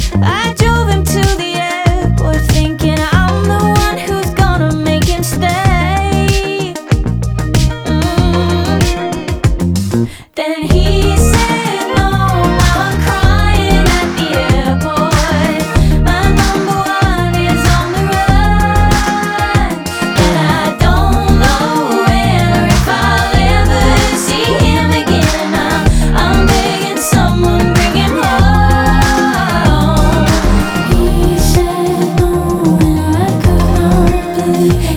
Pop Alternative
Жанр: Поп музыка / Альтернатива